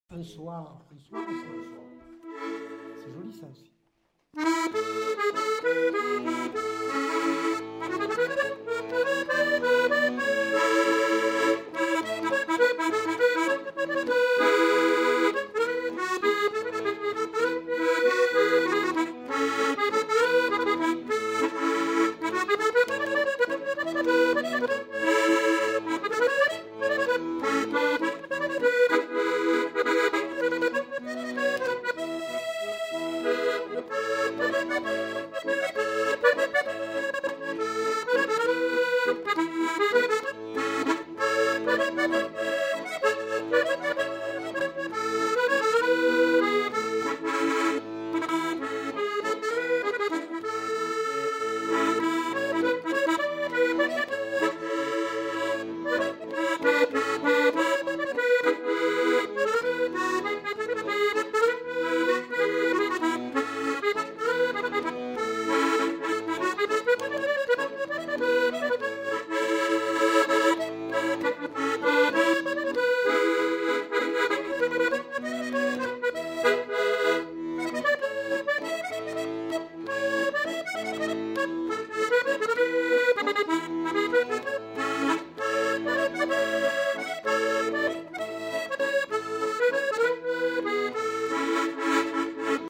Aire culturelle : Quercy
Lieu : Aurillac
Genre : morceau instrumental
Instrument de musique : accordéon chromatique
Danse : valse